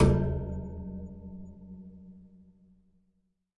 电风扇金属烤架样品 " 电风扇烤架 打1
描述：电风扇作为打击乐器。击打和刮擦电风扇的金属格栅可以发出美妙的声音。
Tag: 金属的 混响 电动风扇 样品